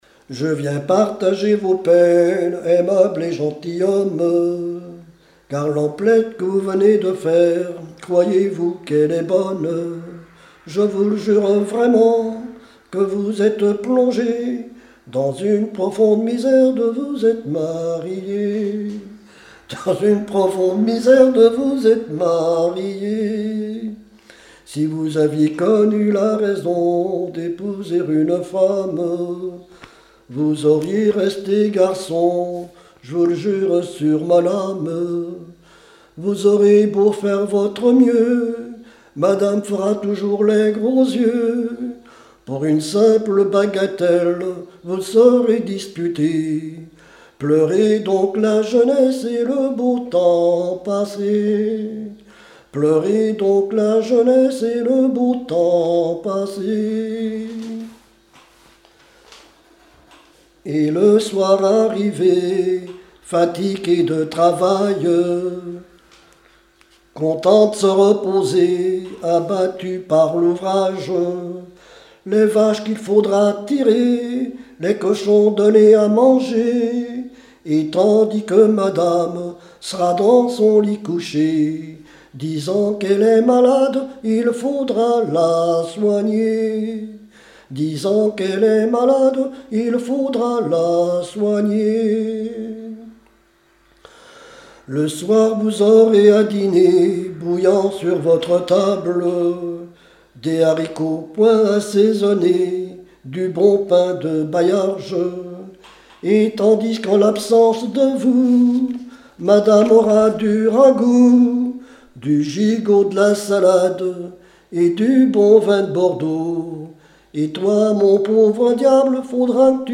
Genre strophique
Enquête Arexcpo en Vendée-Pays Sud-Vendée
Catégorie Pièce musicale inédite